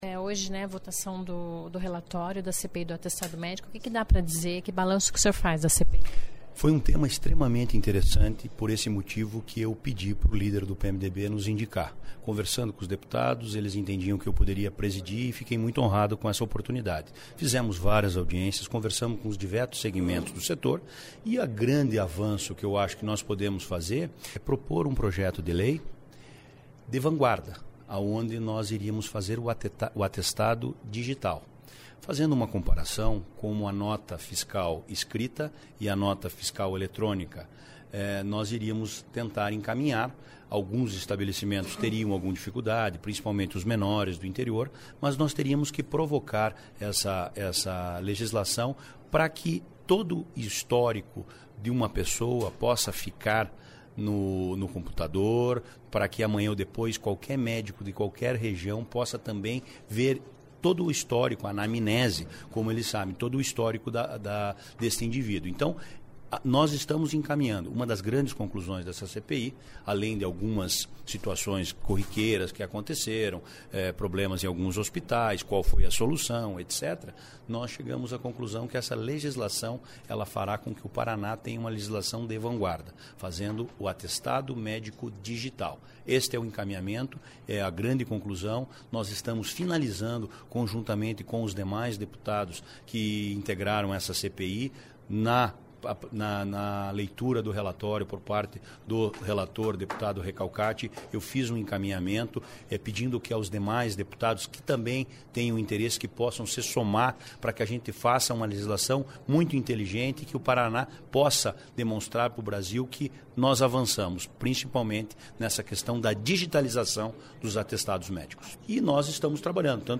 O objetivo é digitalizar os atestados para evitar fraudes, disse o presidente da CPI, deputado Anibelli Neto (PMDB). Confira a entrevista com o parlamentar.